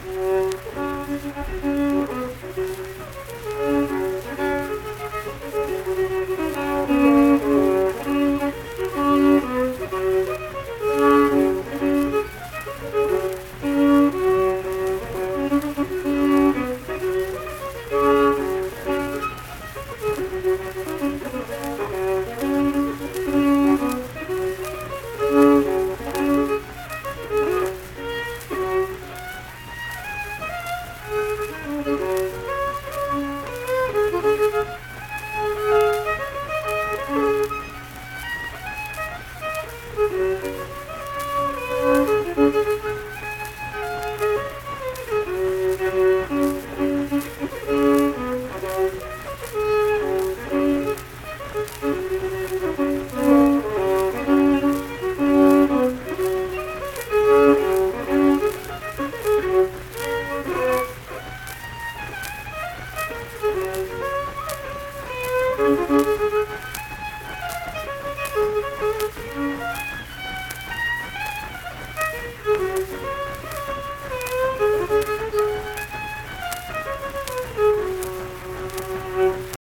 Unaccompanied fiddle music
Verse-refrain 2(2). Performed in Ziesing, Harrison County, WV.
Instrumental Music
Fiddle